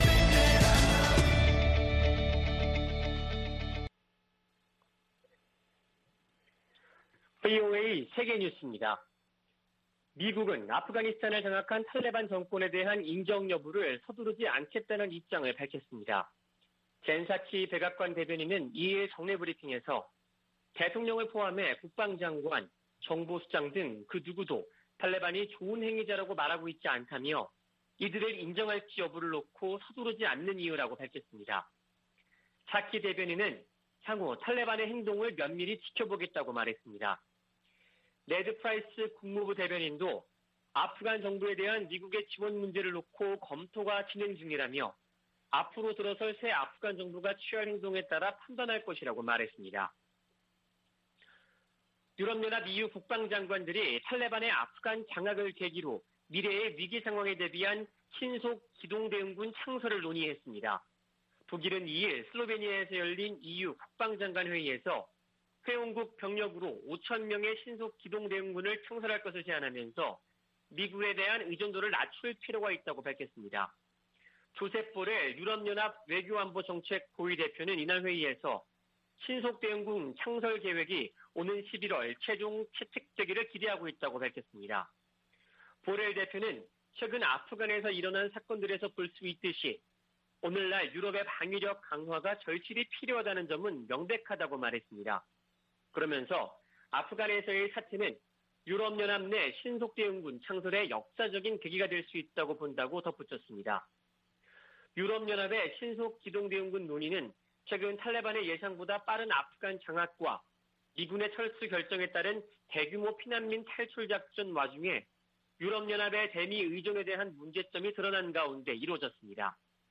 VOA 한국어 아침 뉴스 프로그램 '워싱턴 뉴스 광장' 2021년 9월 4일 방송입니다. 북한이 열병식을 준비하는 것으로 추정되는 모습이 포착됐습니다. 미국의 전직 관리들은 국무부의 북한 여행금지 연장 조치를 지지한다고 밝혔습니다. 주한미군 규모를 현 수준으로 유지해야 한다는 내용이 포함된 미국의 2022회계연도 국방수권법안이 하원 군사위원회에서 의결됐습니다.